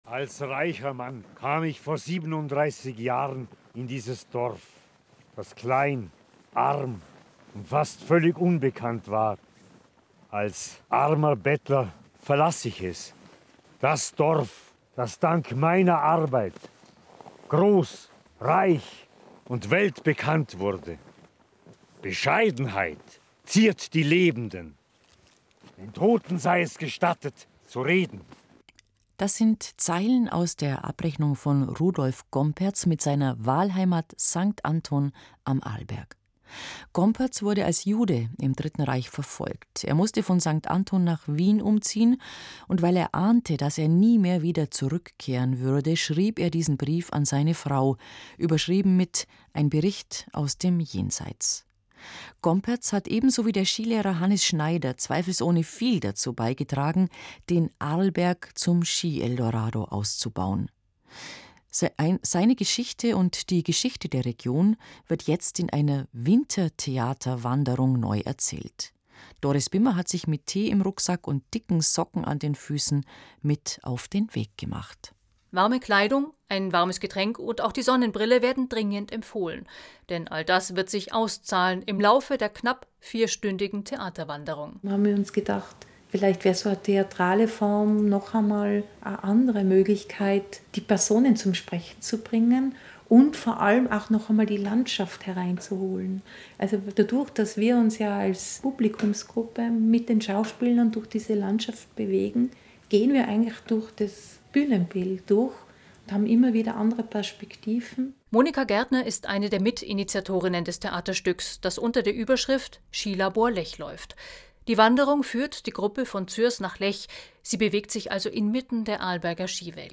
Hier können Sie sie nachhören, inkl. einiger Szenenmitschnitte.
Mitschnitt-Wintertheaterwanderung-Arlberg.mp3